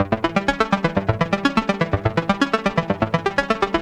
SYNTH GENERAL-3 0002.wav